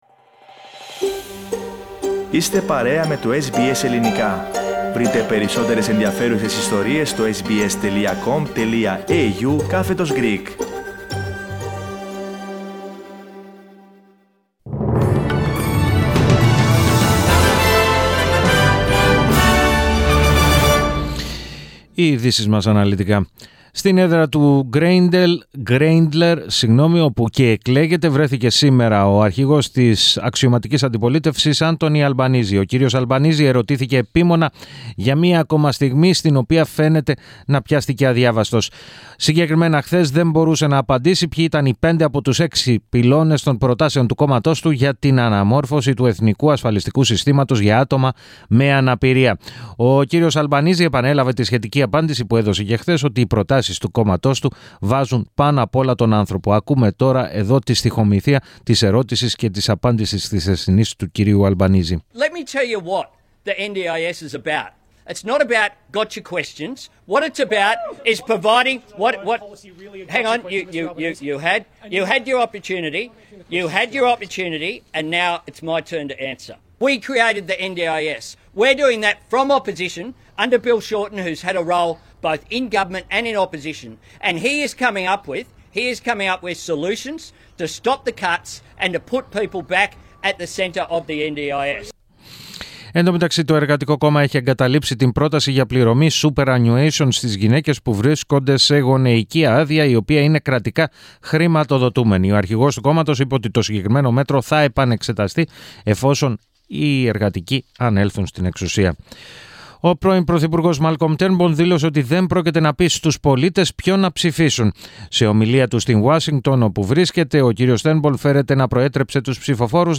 Δελτίο Ειδήσεων 06.05.22
News in Greek. Source: SBS Radio